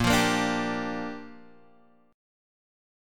A#6 chord